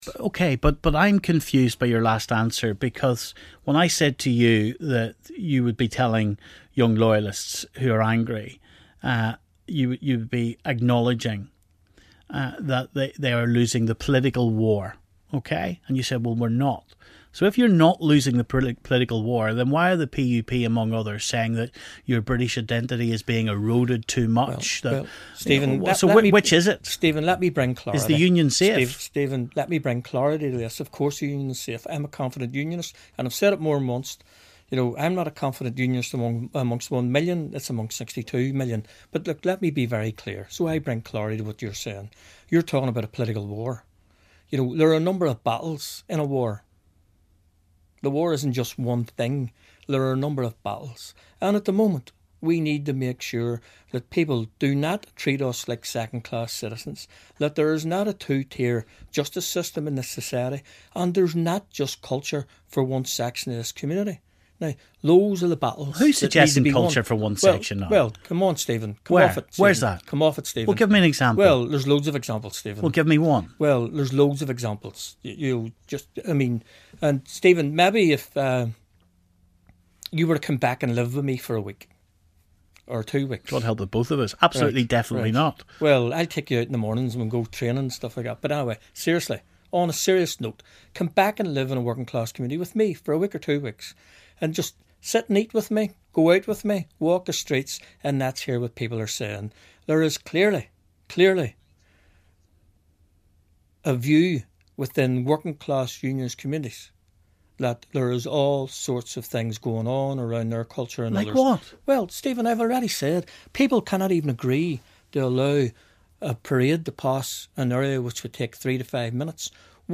He's live in the studio with Stephen